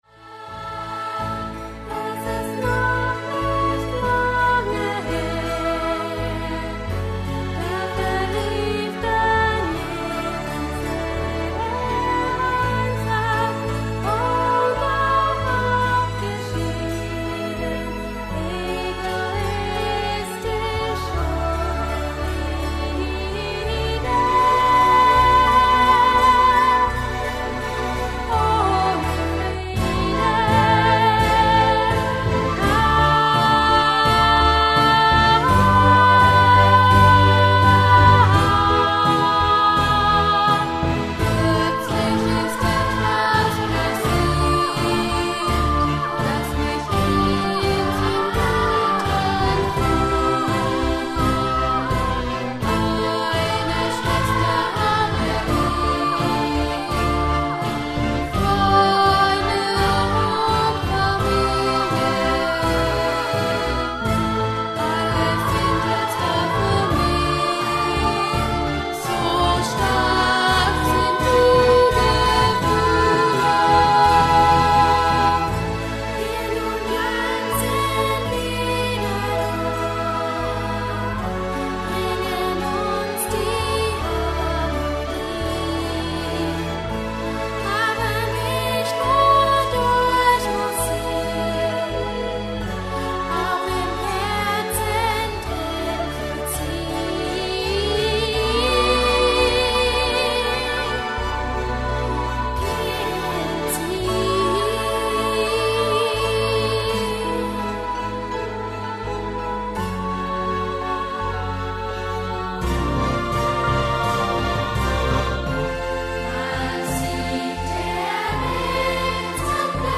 Musical in zwei Akten für Jugendchor und Begleitung
Chor einstimmig, Backgroundchor (ein- bis dreistimmig)